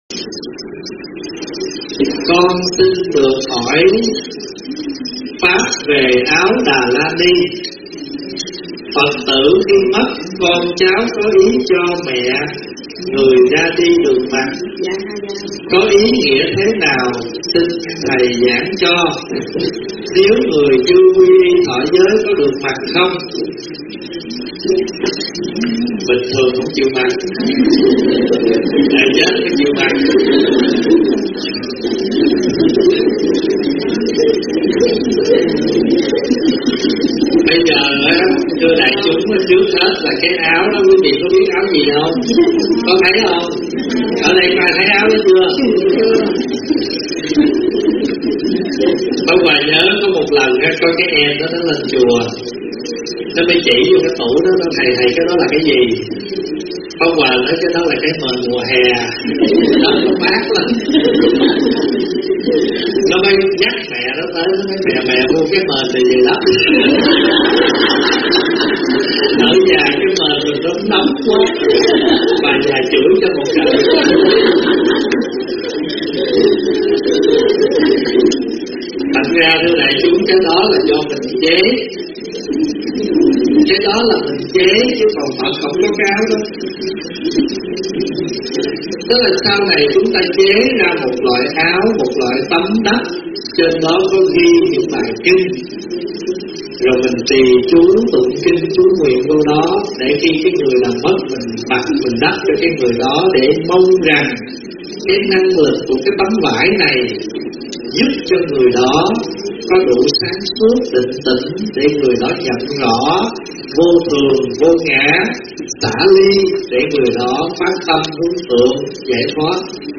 Mời quý phật tử nghe mp3 vấn đáp Ý Nghĩa "Áo Đà La Ni" do ĐĐ.